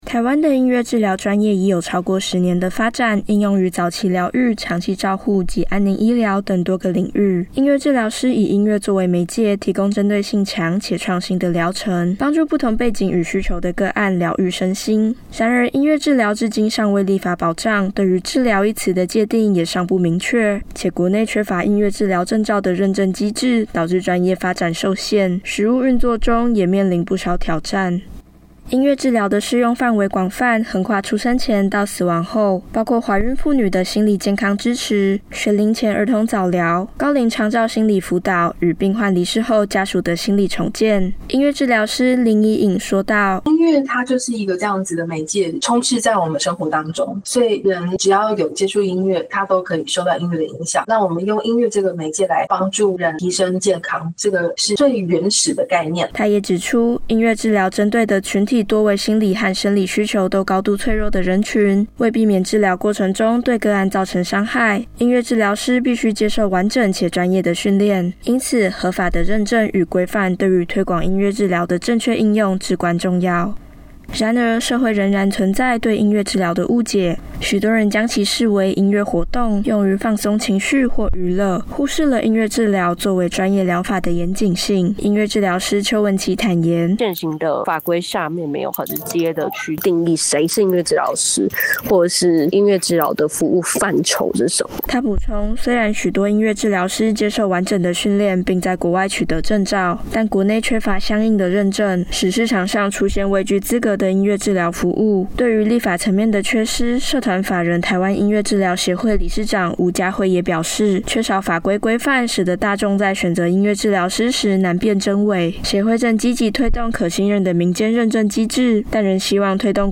政大之聲實習廣播電台-新聞專題